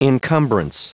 Prononciation du mot encumbrance en anglais (fichier audio)
Prononciation du mot : encumbrance